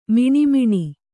♪ miṇi miṇi